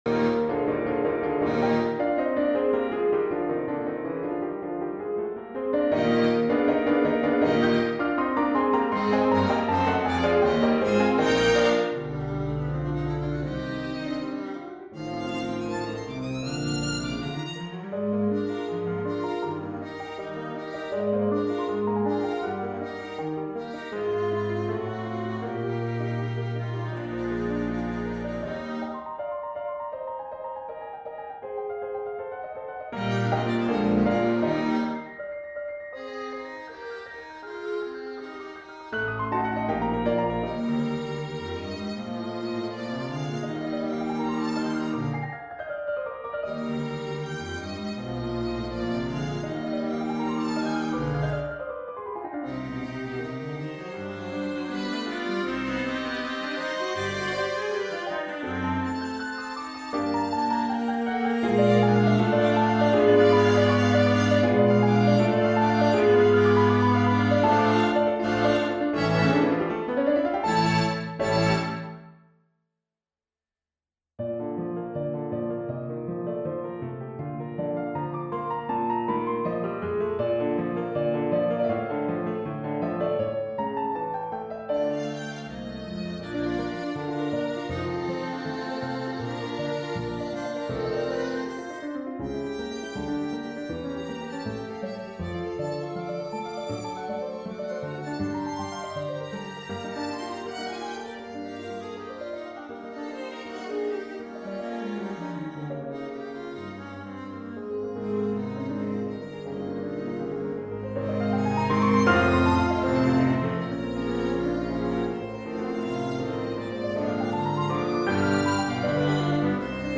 Soundbite 1st Movt
have had to resort to midi files
For Violin, Viola, Cello, Bass and Piano